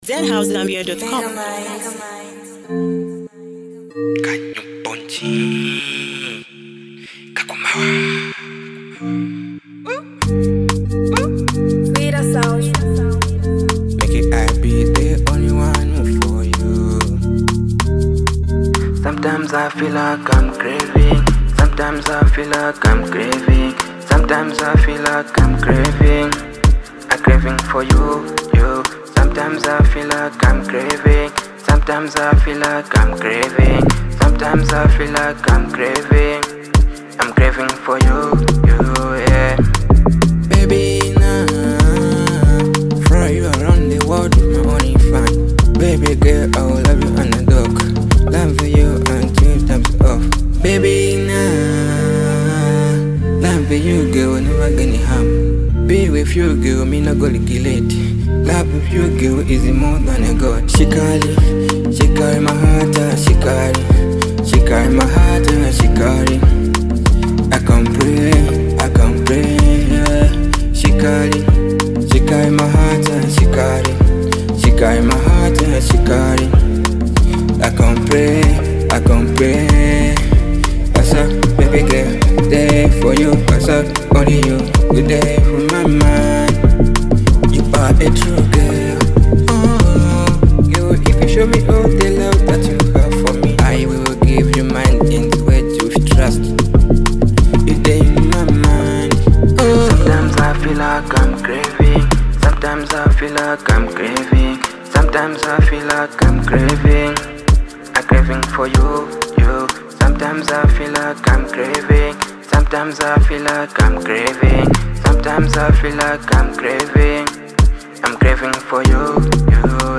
a smooth Afro vibe